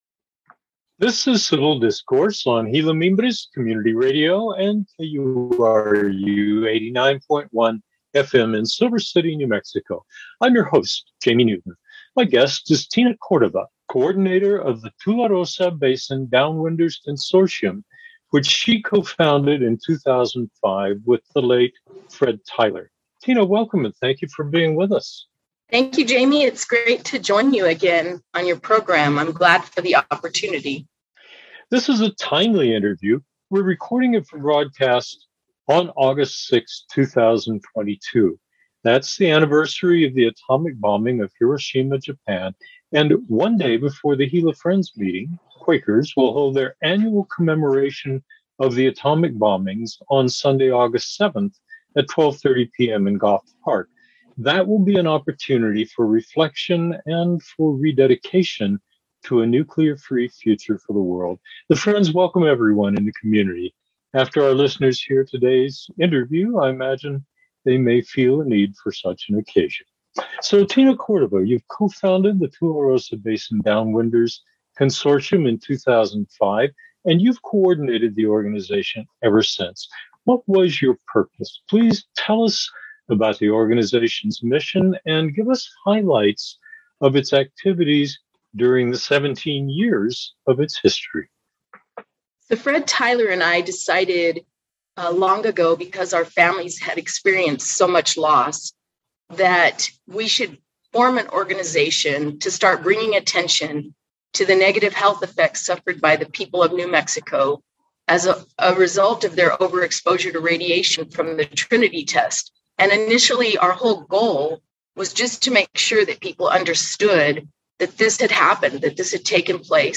In this interview